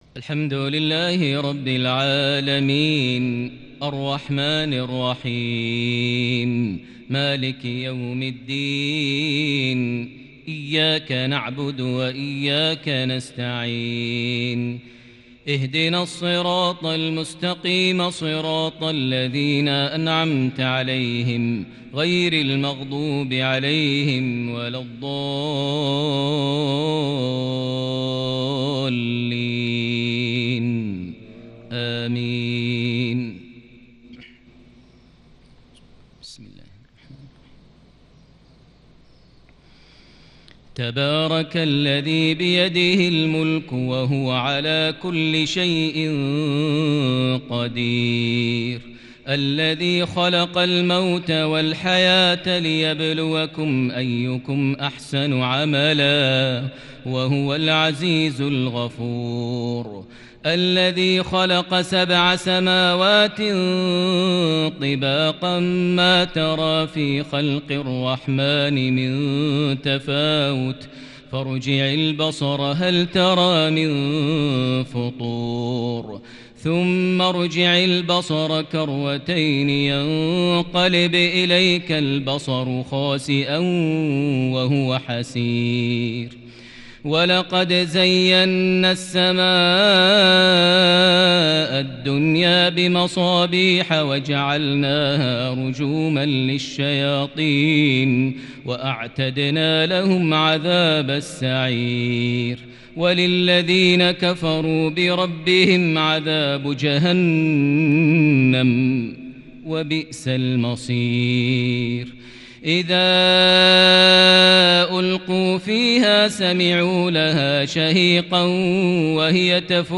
عشائية شجية فريدة بالكرد من سورة الملك (1-18) | الجمعة 23 محرم 1442هـ > 1442 هـ > الفروض - تلاوات ماهر المعيقلي